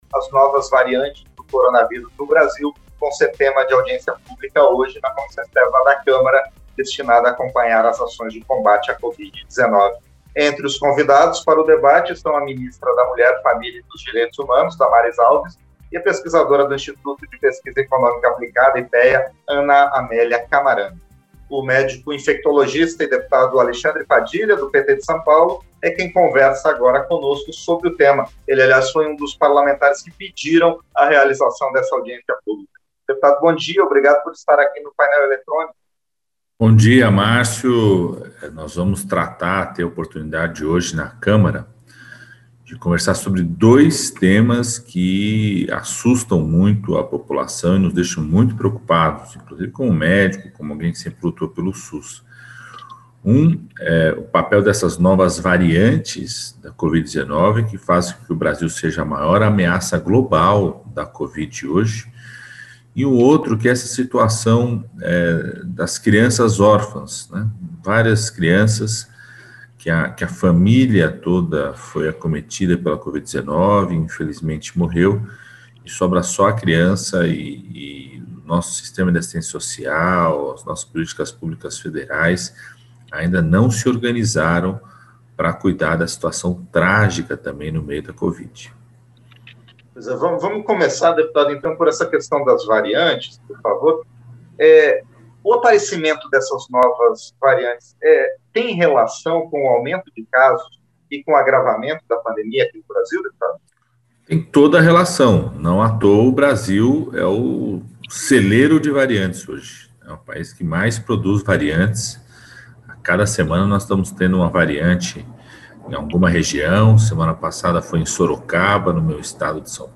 Entrevista  – Dep. Alexandre Padilha (PT-SP)